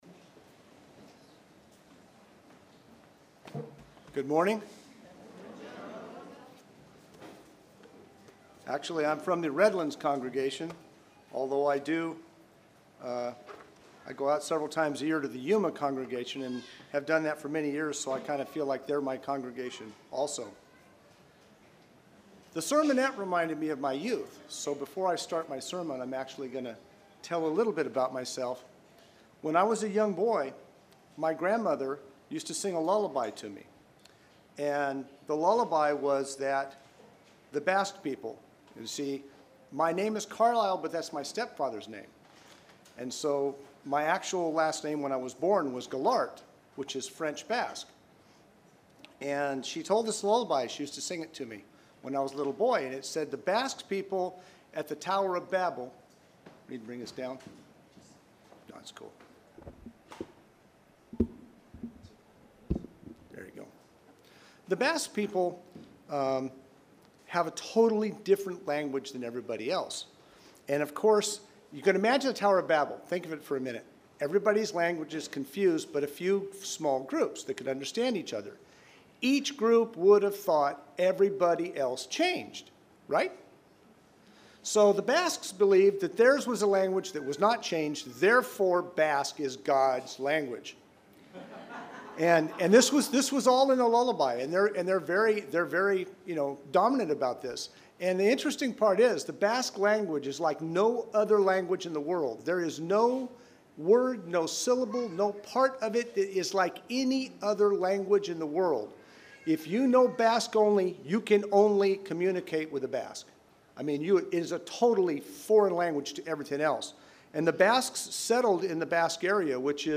English message